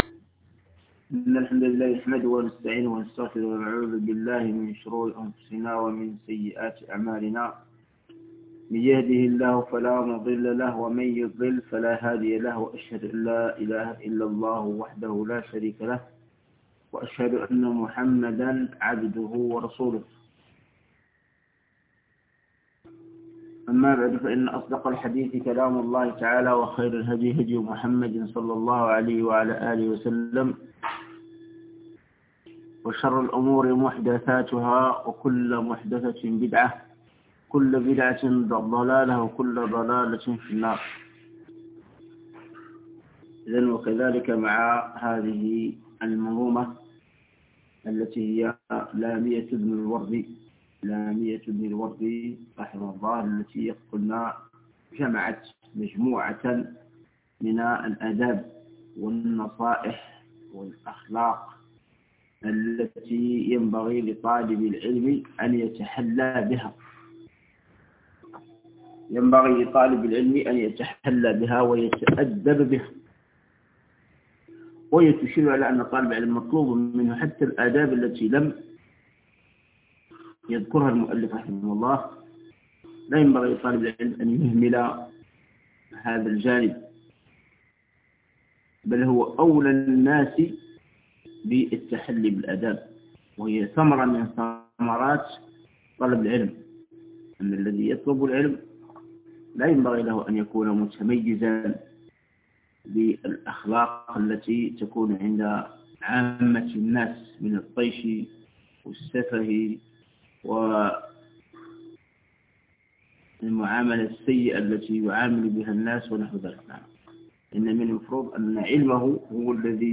شرح لامية ابن الوردي الدرس 3